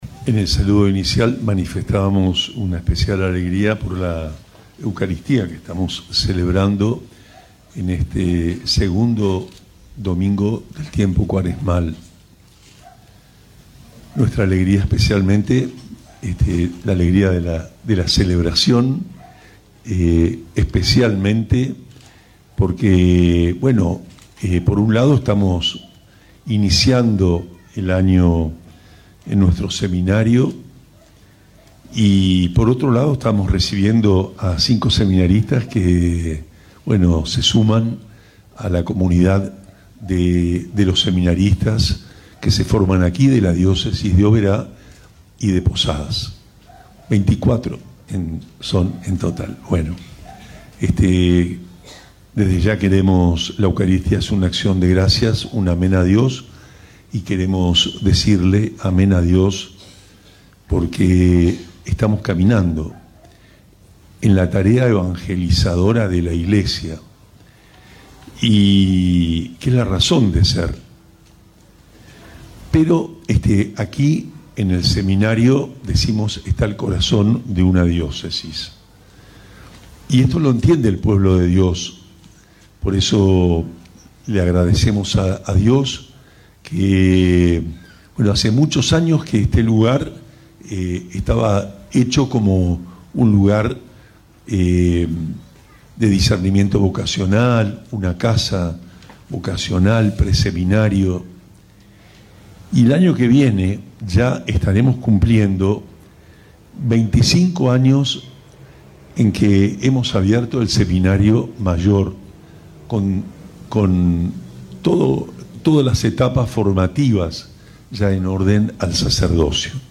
Durante la misa celebrada en su predio de avenida Leandro N. Alem 3157, el obispo de Posadas, monseñor Juan Rubén Martínez, presidió la Eucaristía y dejó un mensaje sobre la vocación, la experiencia de Dios y el compromiso misionero de la Iglesia.
Escuchá la homilía completa de Mons. Juan Rubén Martínez: